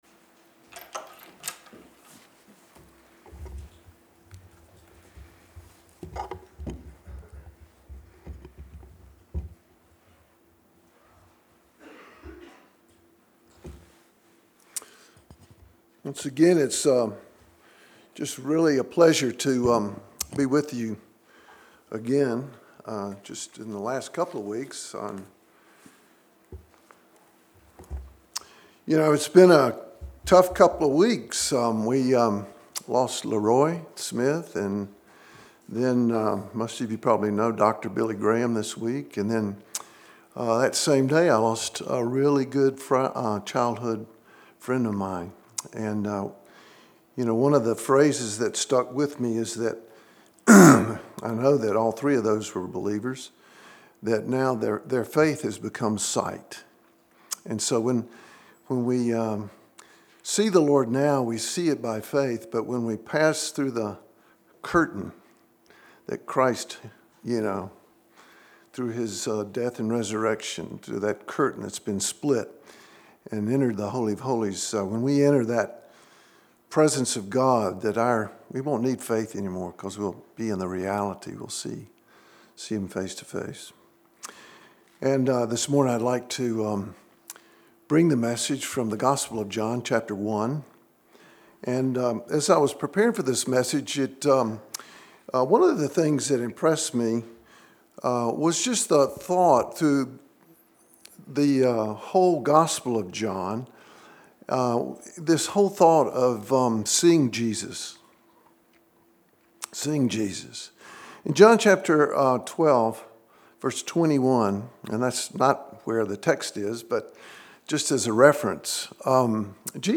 February 25, 2018 (Sunday Morning)